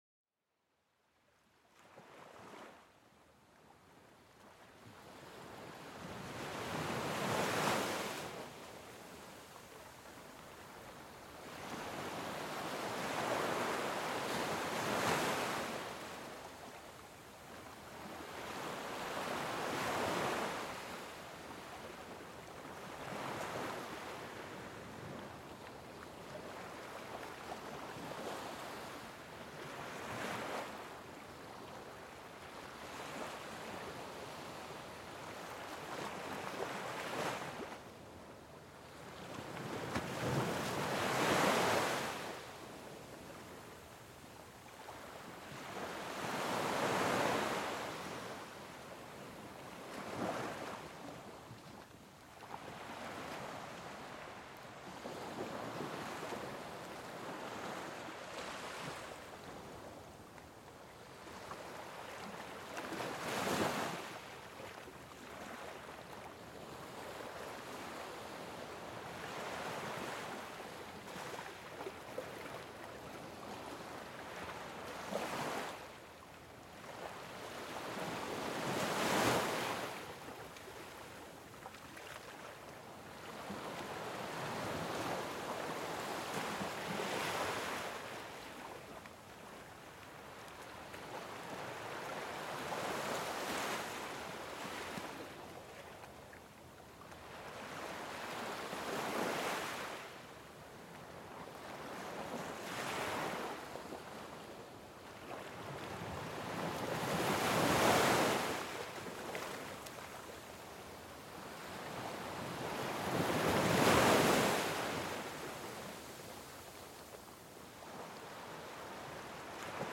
Laissez-vous bercer par le doux roulis des vagues sur le rivage, un son qui apaise l'esprit et vous transporte vers des plages paisibles. Chaque vague offre un rythme naturel qui aide à relâcher les tensions et à calmer le mental.